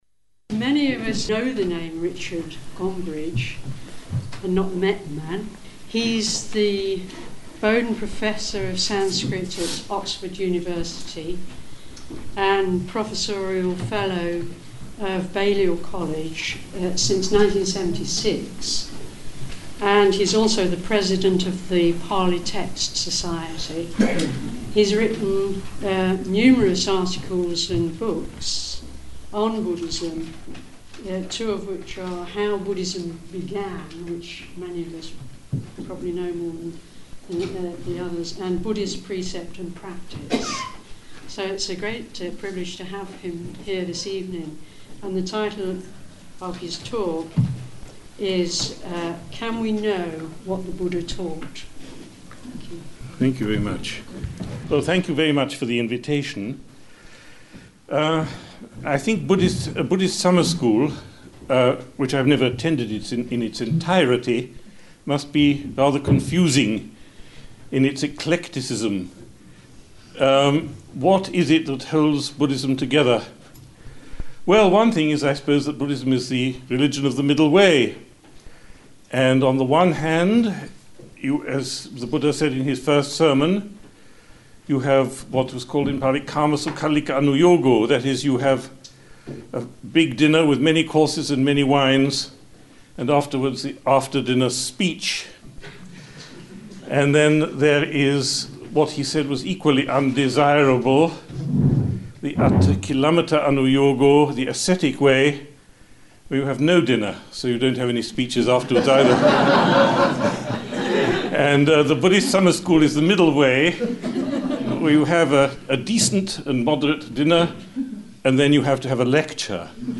Talk by Richard Gombrich in which he explores the difficult issue of whether we can know what the Buddha taught, using the Pali texts as his basis. 46 minutes.
This talk was given at the 2002 BPG Leicester Buddhist Summer School.